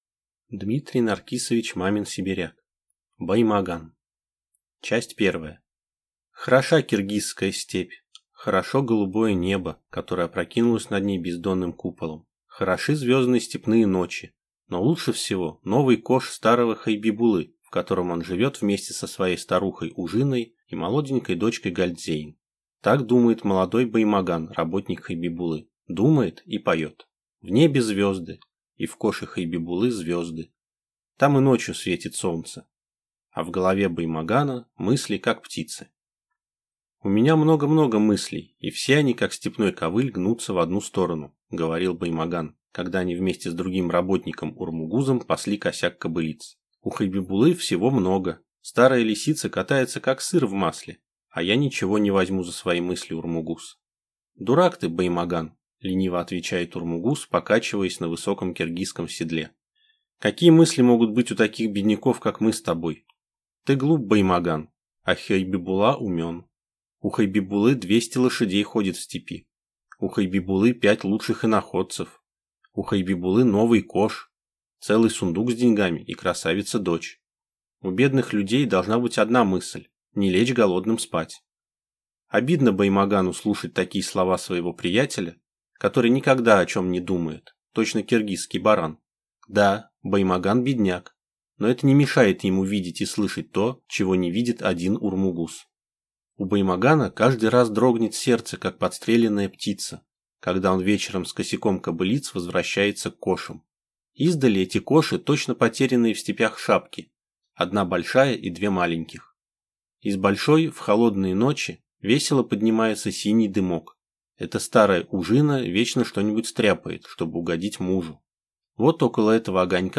Аудиокнига Баймаган | Библиотека аудиокниг